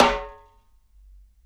SNARE OFF RIMSHOT.wav